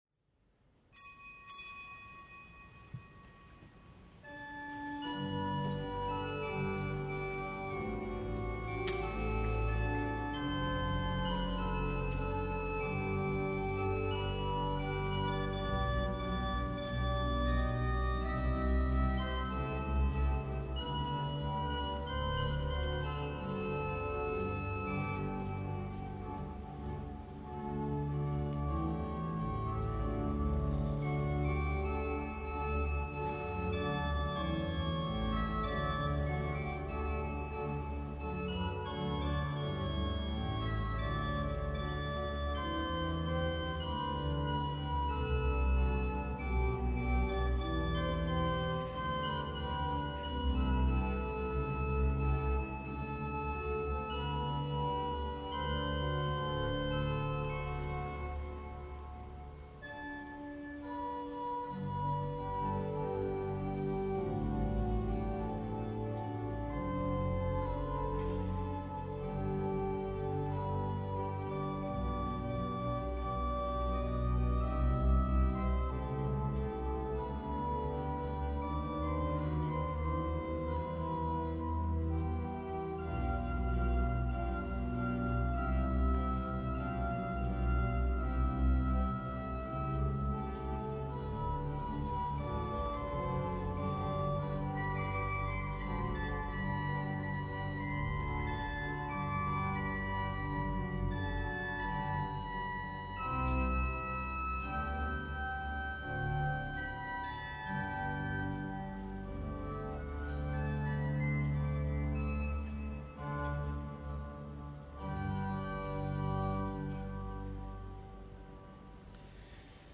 Wortgottesdienst aus dem Kölner Dom für Paare am Valentinstag.